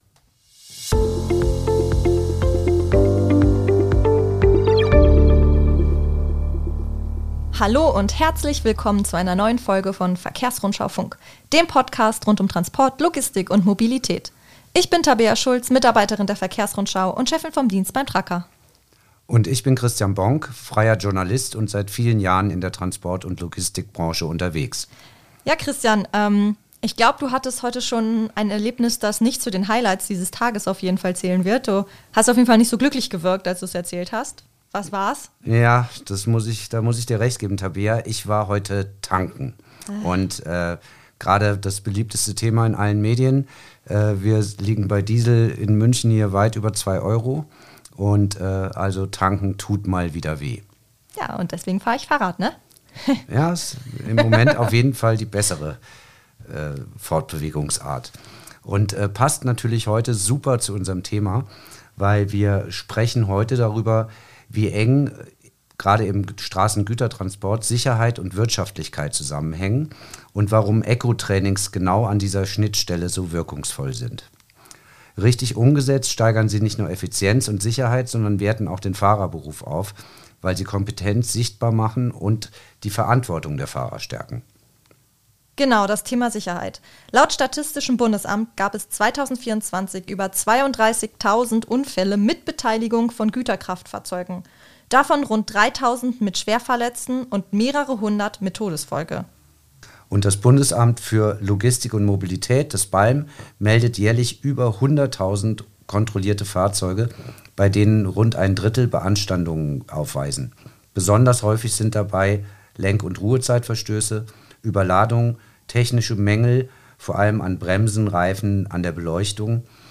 Sie erklären, wie professionelles Eco‑Fahren Verschleiß, Verbrauch und Stress reduziert und warum Sicherheitstrainings heute weit über Bremsübungen hinausgehen. Im Mittelpunkt stehen Praxiserfahrungen aus Realverkehrstrainings, Controlling‑Ansätze und Prämiensysteme, die Fahrerleistungen sichtbar machen. Im Interview